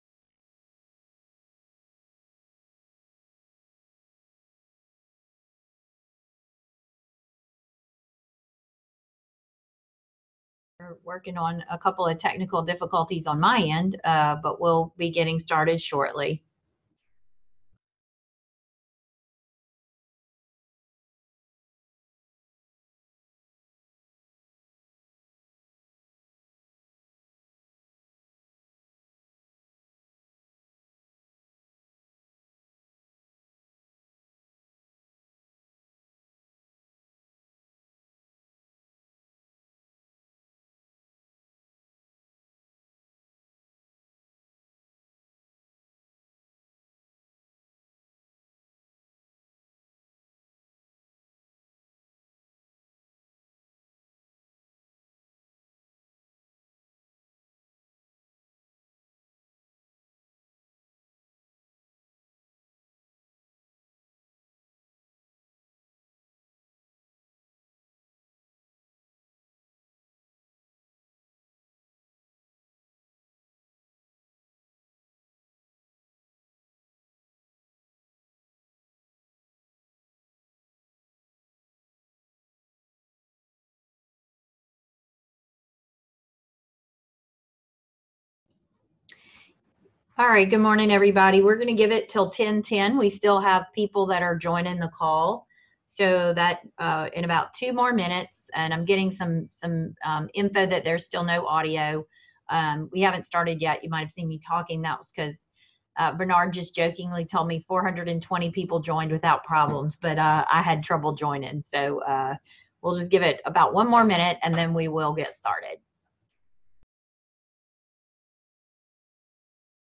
OCDDHCBSWaiverRateIncreaseWebinarAudioOnly.mp3